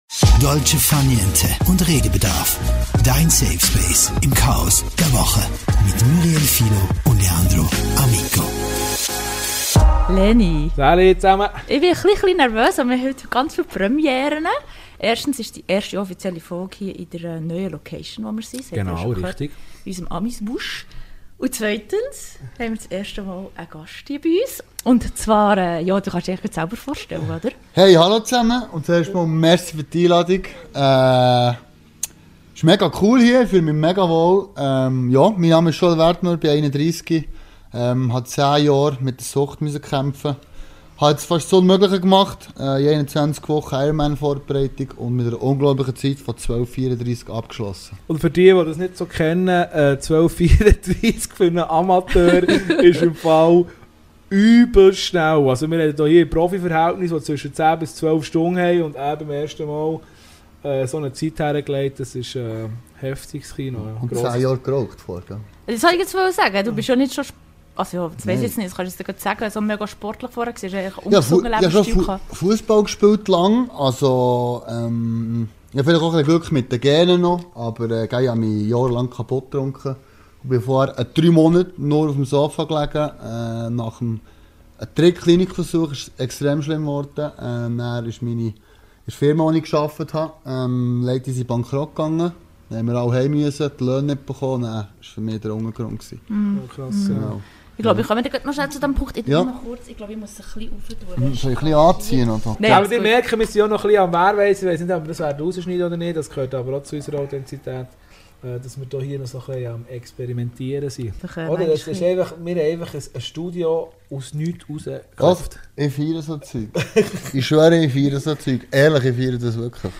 Gemeinsam sprechen wir über den Weg aus der Sucht, moderne Therapieansätze und die mentale Stärke, die nötig ist, um wirklich neu anzufangen. Ein ehrliches, tiefgehendes und inspirierendes Gespräch über Rückfälle, Motivation und Zukunftspläne.